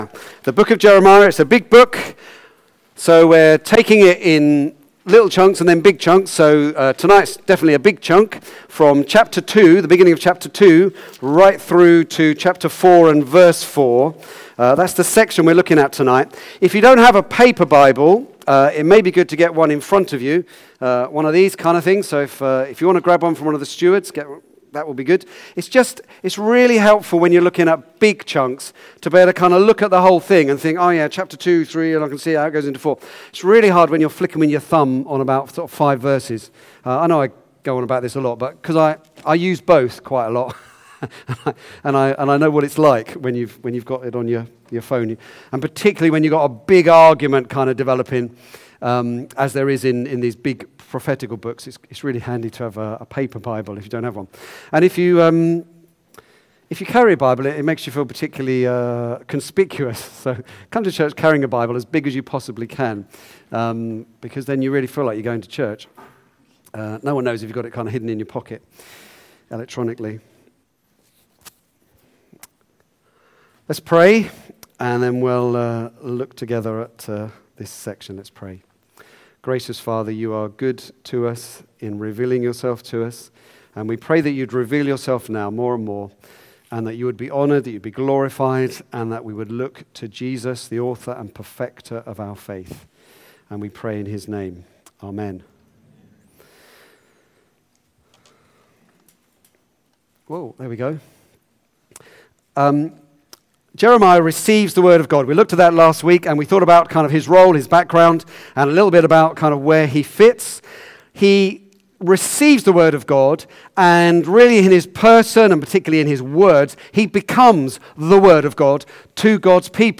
Back to Sermons The pleas of a scorned husband